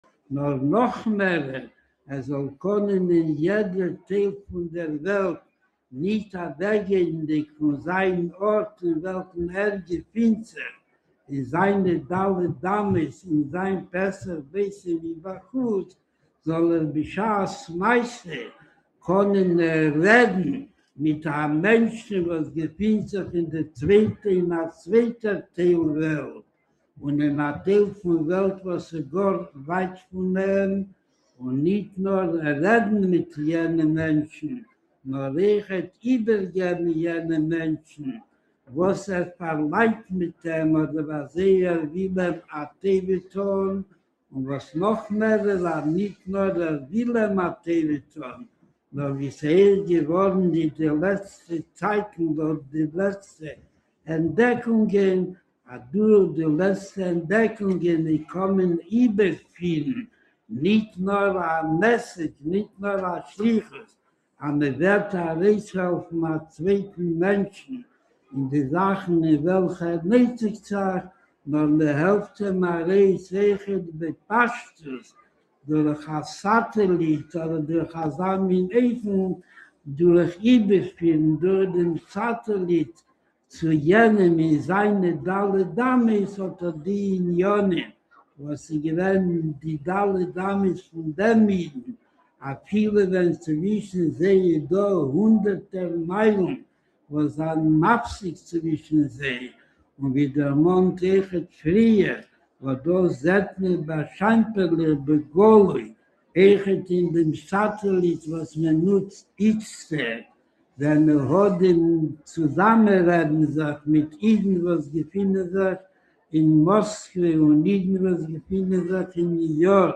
היה זה ביום ראשון, כ"ד כסלו תשנ"ב. בבית מדרשו של הרבי, הסתיימה זה עתה תפילת מנחה, והנה ניגשים כעת למעמד הדלקת נר ראשון של חנוכה, המשודר בשידור חי למספר נקודות ברחבי העולם.